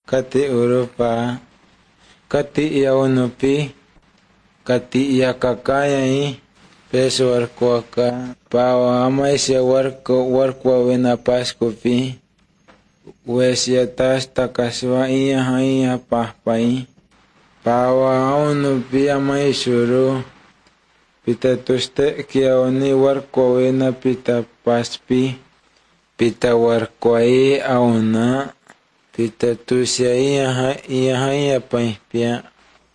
31 January 2017 at 11:20 pm Okay, I hear no aspirated or ejective consonants (so it’s not Mayan) and no high central vowel [ɨ] (so it’s not Garifuna/Arawakan). It’s not clear if there are distinct mid vowels [e o] – I hear some but they seem to vary with [i u], e.g. the [kate] at the beginning gets repeated as [katɪ] and [kati] later.
I do hear lots of nasalized vowels (so it’s probably *not* Misumalpan), and all the obstruents sound simply voiceless unaspirated to me, suggesting no voicing distinction.